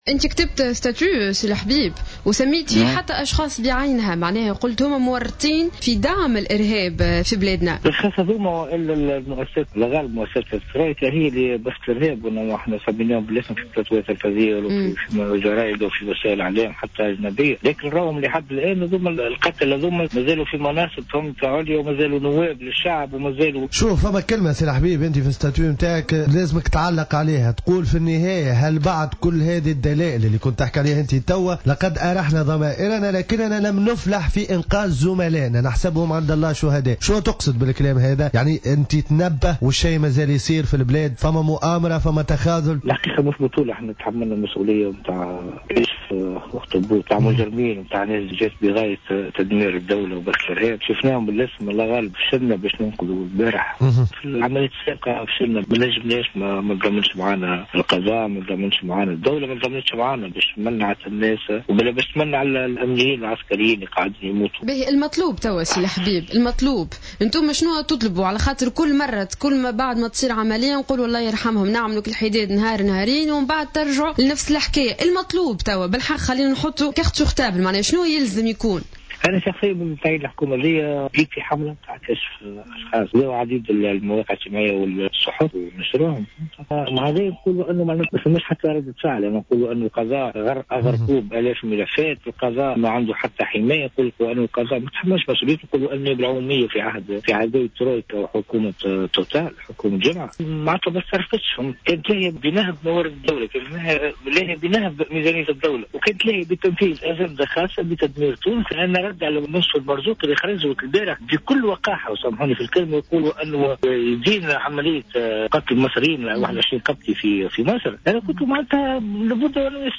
مداخلة
في برنامج" صباح الورد" على جوهرة أف أم